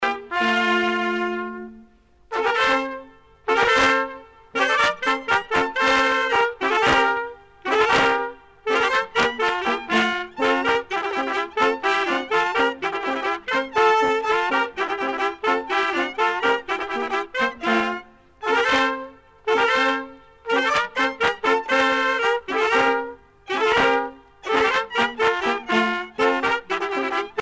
danse : polka
groupe folklorique
Pièce musicale éditée